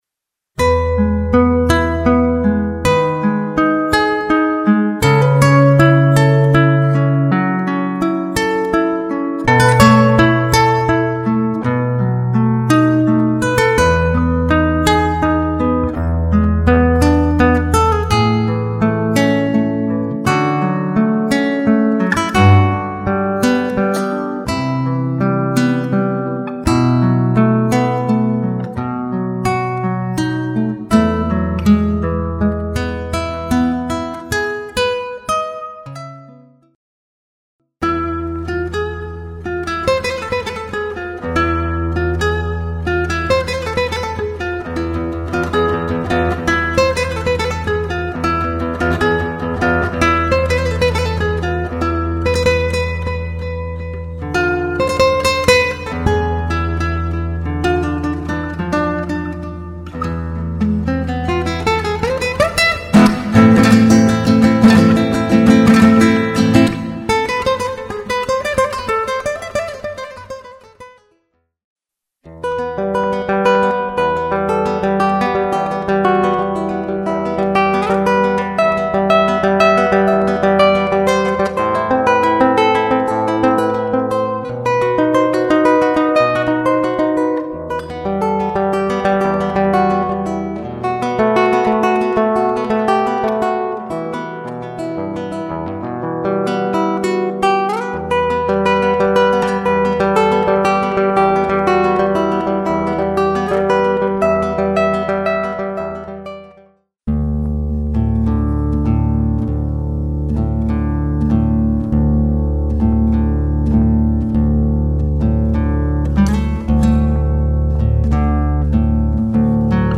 Spanish Classical Guitar Medley